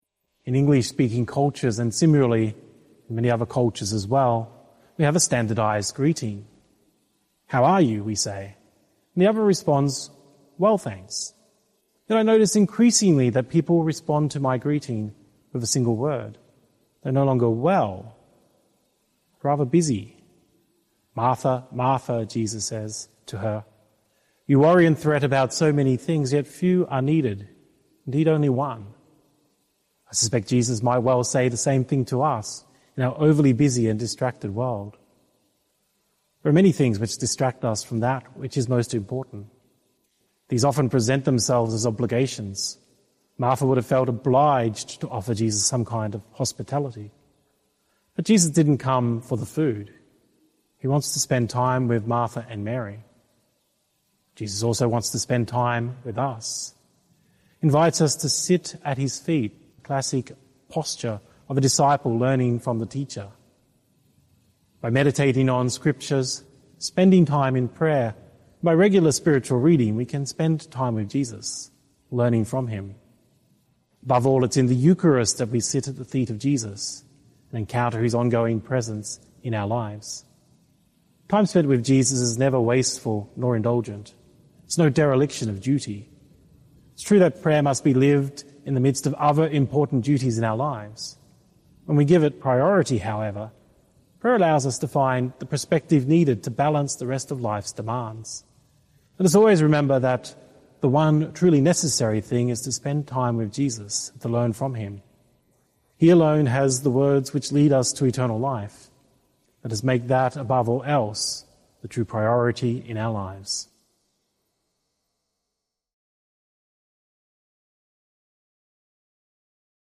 Archdiocese of Brisbane Sixteenth Sunday in Ordinary Time - Two-Minute Homily